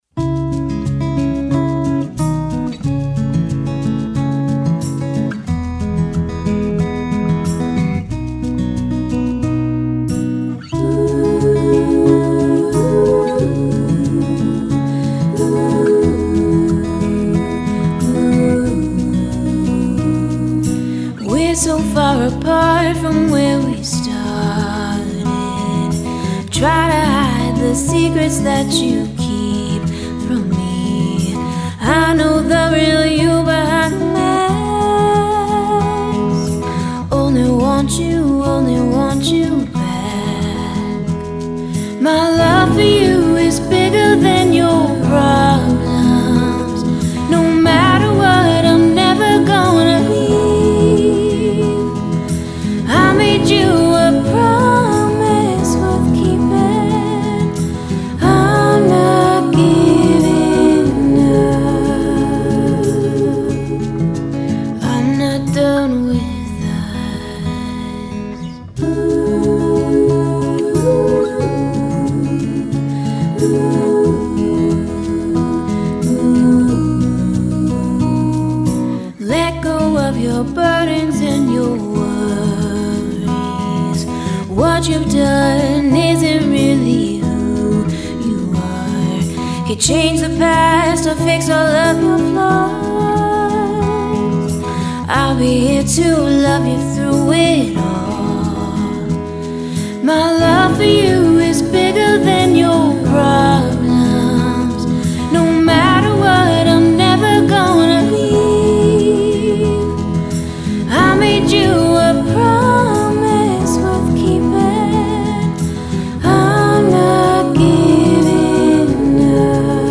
MASTER RECORDINGS - Country